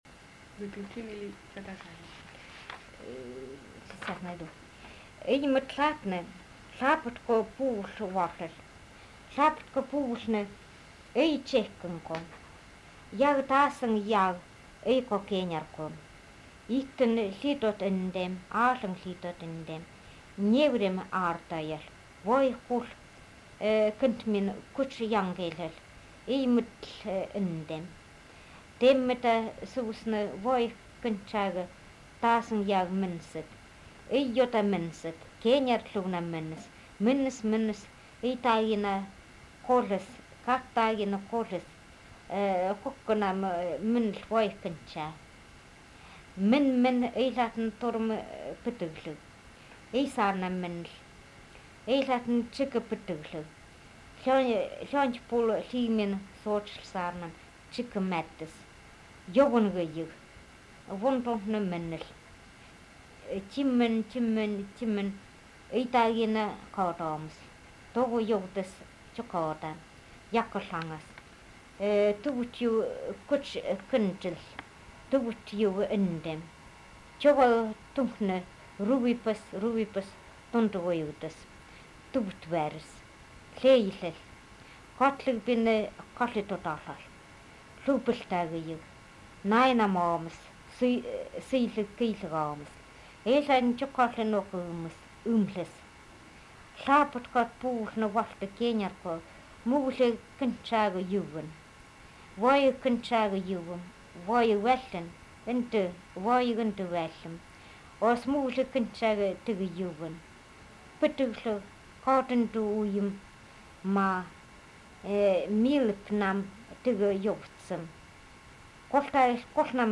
Tales (tal)